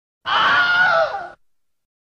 scream